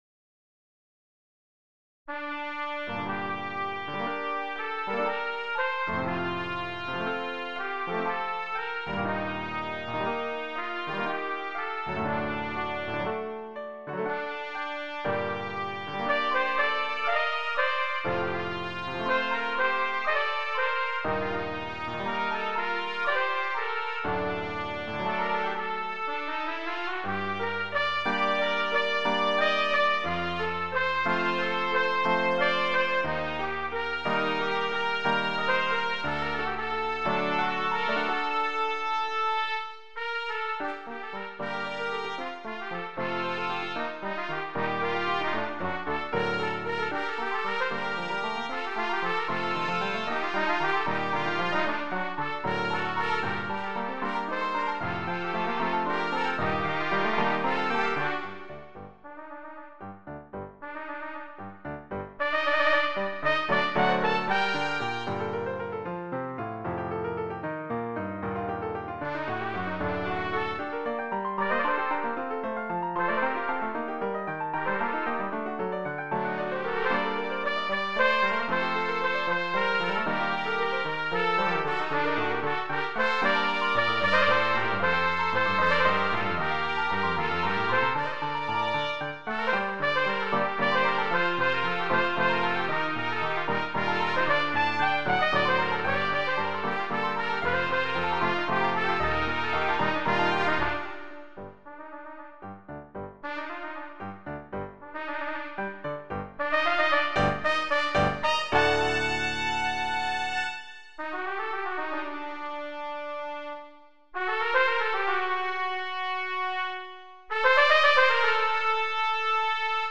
Voicing: Trumpet Duet and Piano